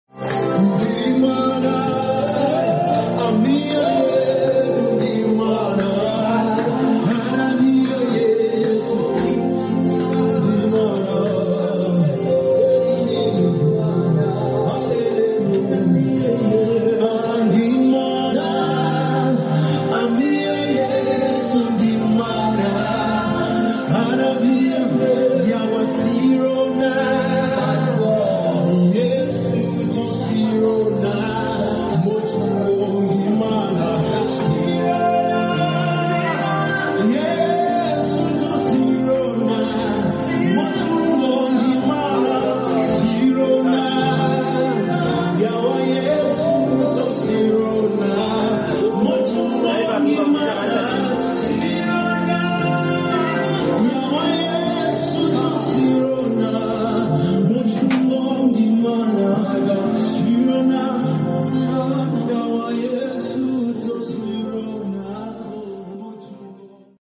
worship song